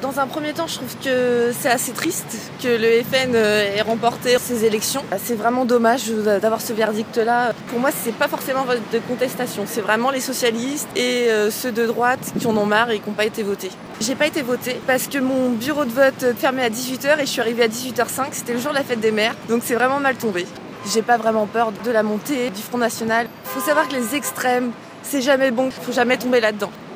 Ambiance et micro-trottoir…
Ambiance à la gare Saint-Lazare (Paris), le lieu de plus forte affluence du pays chaque matin.